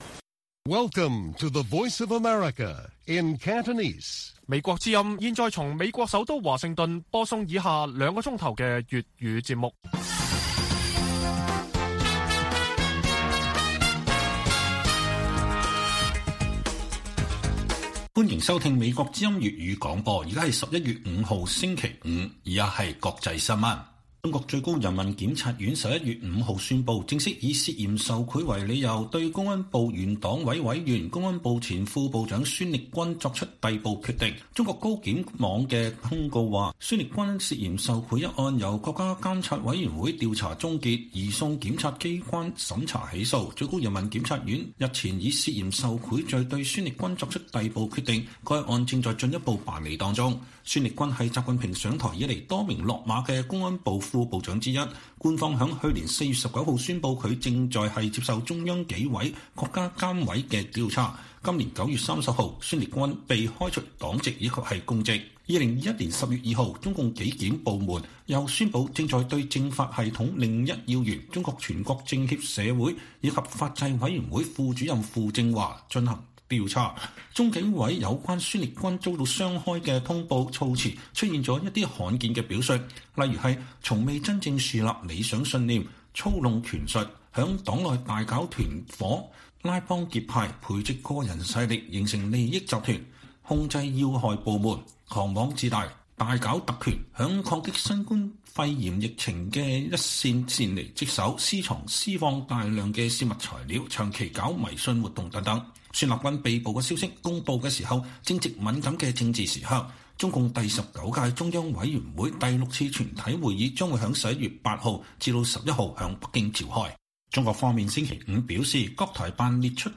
粵語新聞 晚上9-10點: 中國最高檢宣布逮捕前公安部副部長孫力軍